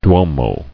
[duo·mo]